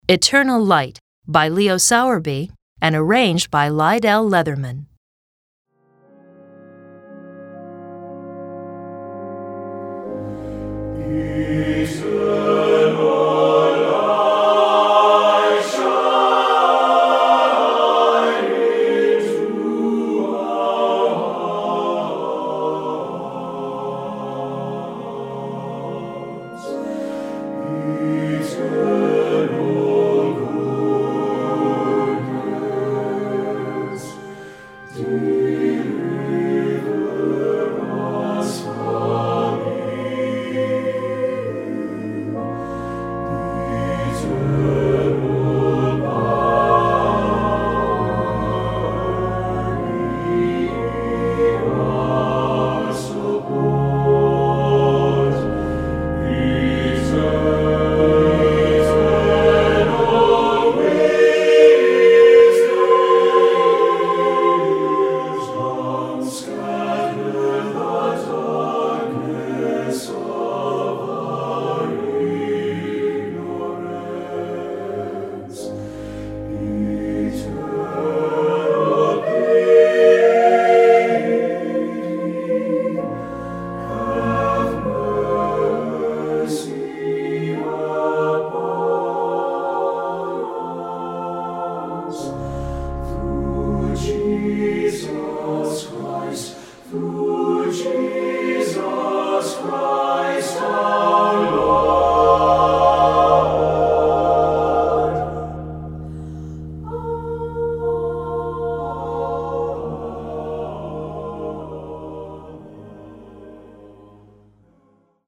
Voicing: SATB divisi and Organ